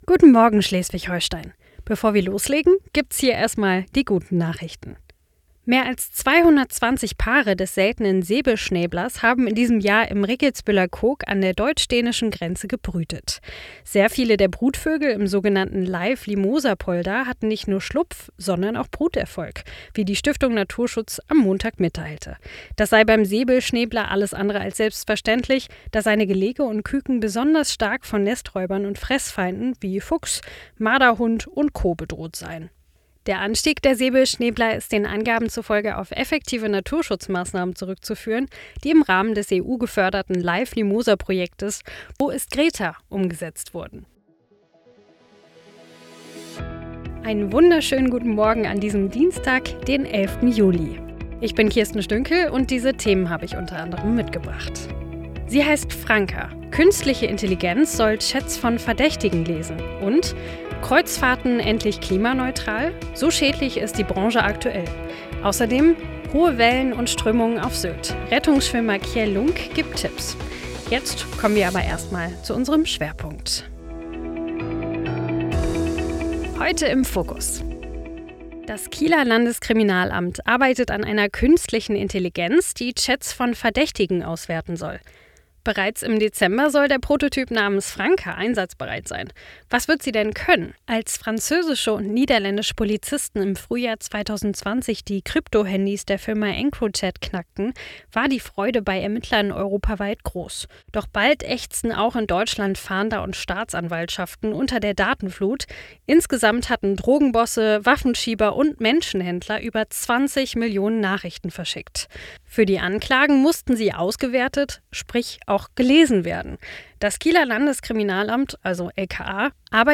Das erfährst Du ab sofort in unserem neuen regionalen News-Podcast für Schleswig-Holstein „Guten Morgen SH“. Heute im Fokus: Das Kieler Landeskriminalamt arbeitet an einer künstlichen Intelligenz, die Chats von Verdächtigen auswerten soll.